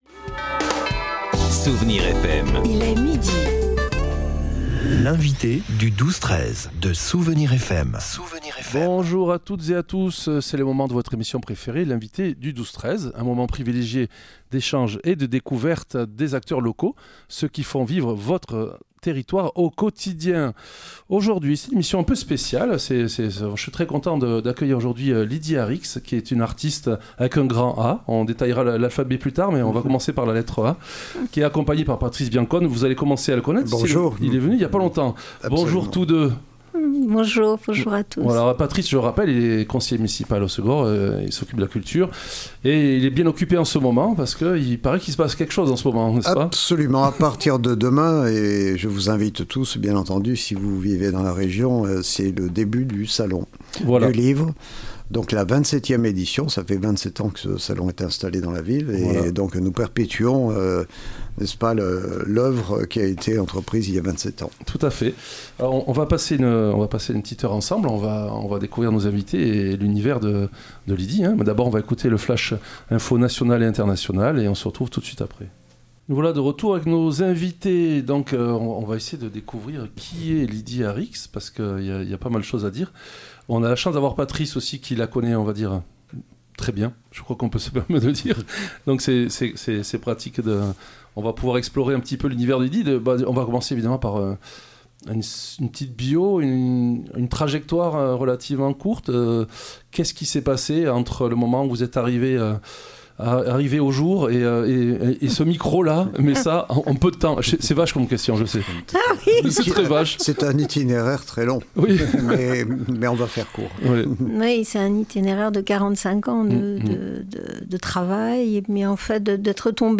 Nous avons eu une conversation passionnante avec Lydie et Patrice sur cet art qui nous est si cher, l'invention , la joie et le bouillonnement créatif de Lydie accompagnés des mots admiratifs et sensibles de Patrice, un grand moment !